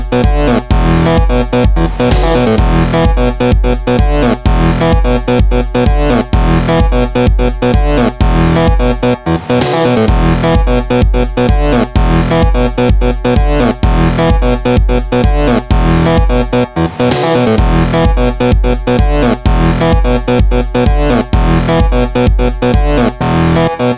no Backing Vocals Pop (2000s) 4:07 Buy £1.50